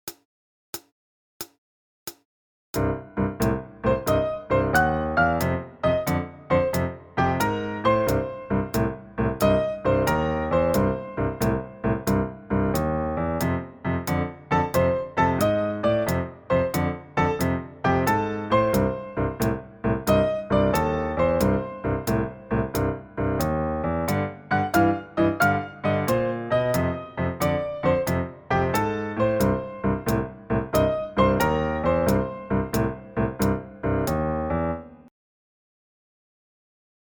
Piano ou Clavier